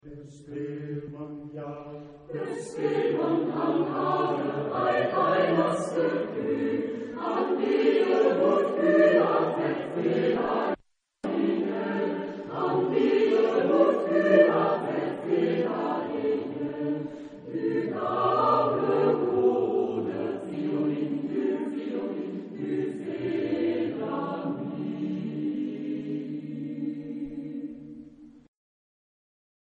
Genre-Stil-Form: weltlich ; Volkstümlich
Charakter des Stückes: fröhlich ; tanzhaft
Chorgattung: SATB  (4 gemischter Chor Stimmen )
Tonart(en): D-Dur